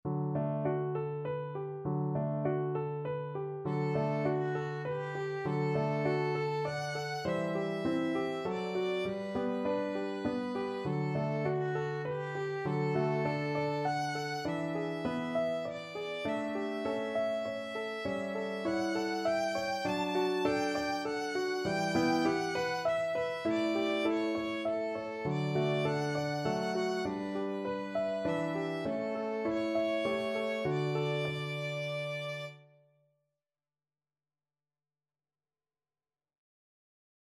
Traditional Trad. Golden Slumbers Violin version
Violin
17th-century English folk song.
D major (Sounding Pitch) (View more D major Music for Violin )
3/4 (View more 3/4 Music)
Moderato